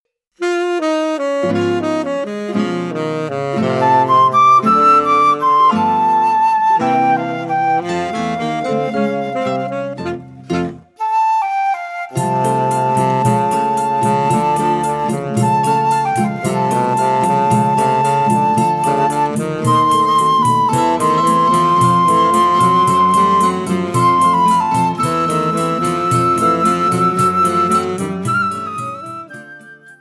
– Full audio with choro ensemble, solo and counterpoint.
flute
tenor saxophone